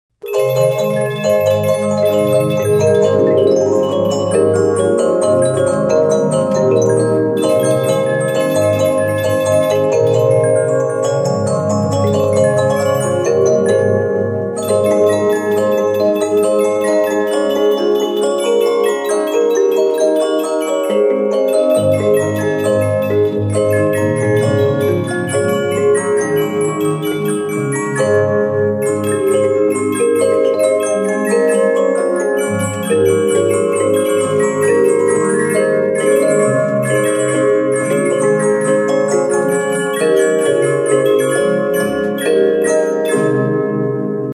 MUSIC BOXES